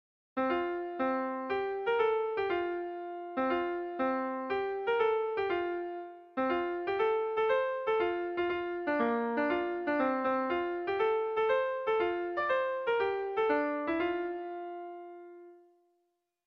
Bertso melodies - View details   To know more about this section
AABDBE